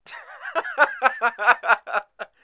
男人尴尬笑声音效_人物音效音效配乐_免费素材下载_提案神器
男人尴尬笑声音效免费音频素材下载